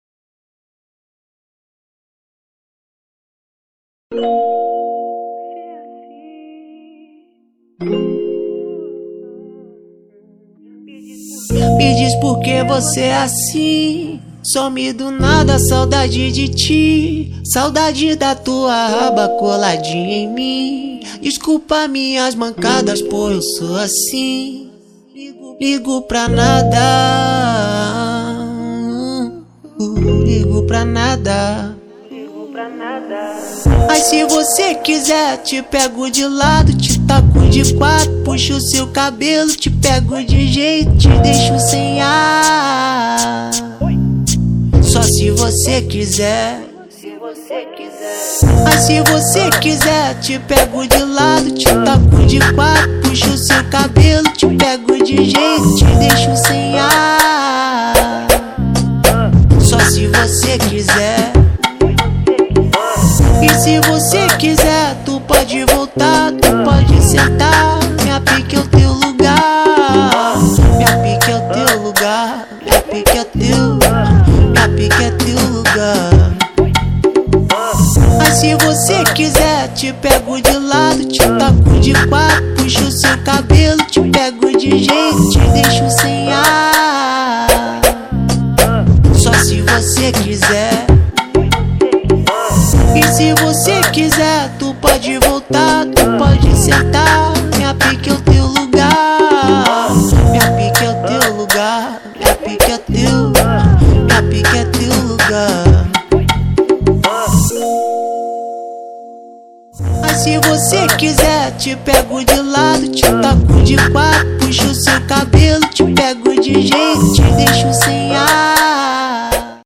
2024-03-05 20:27:29 Gênero: Trap Views